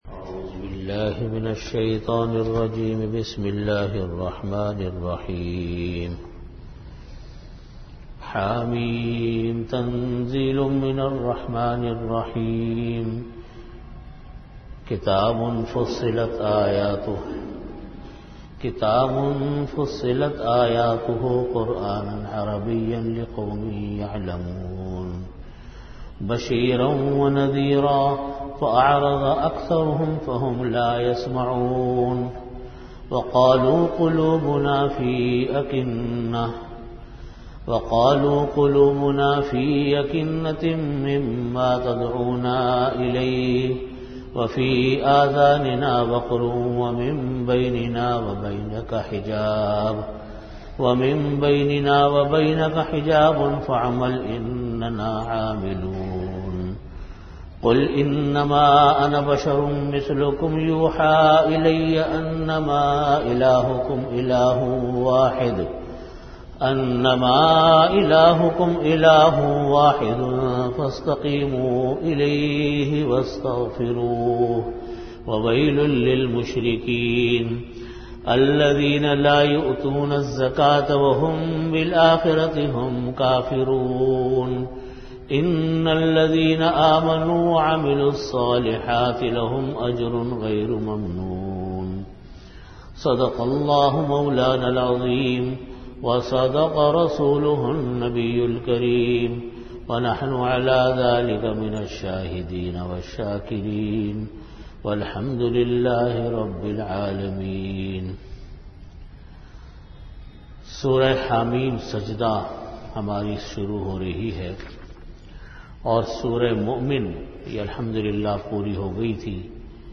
An audio bayan
Originally delivered in After Asar Prayer at Jamia Masjid Bait-ul-Mukkaram, Karachi.